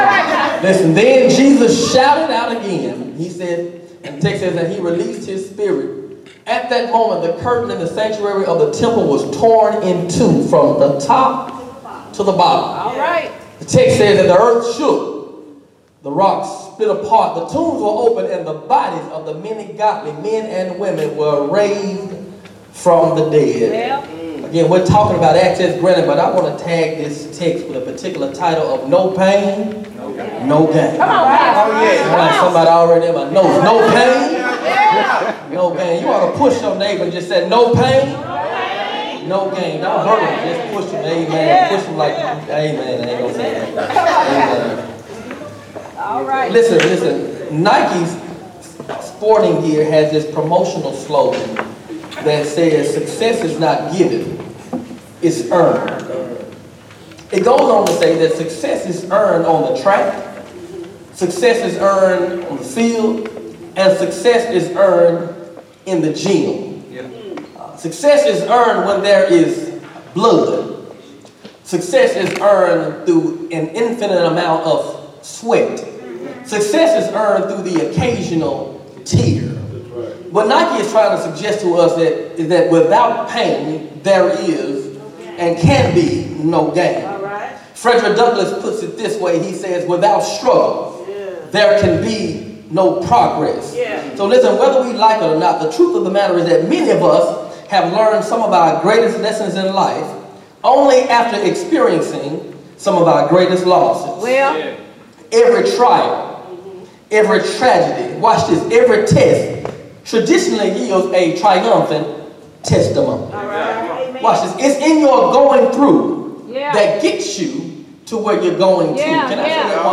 Sermons Archive - REDEMPTION CHURCH